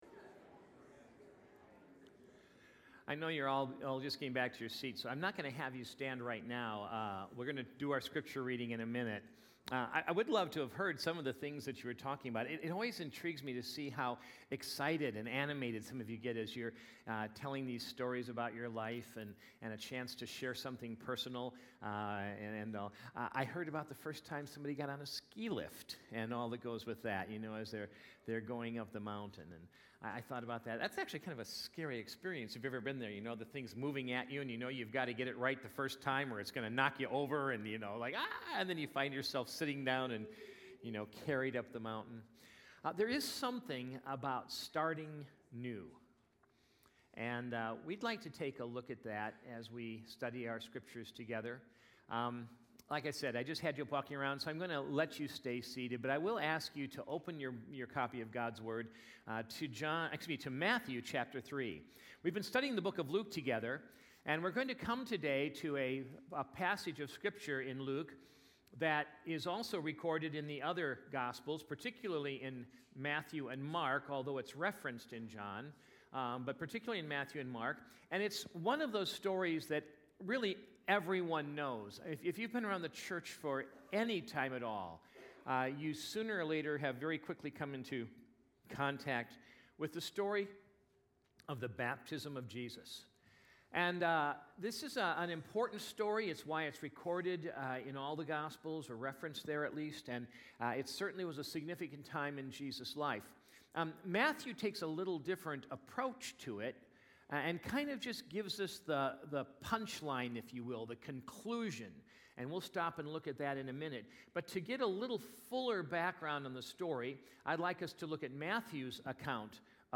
Sunday Morning Message
Sunday_AM_Sermon_1_19_20.mp3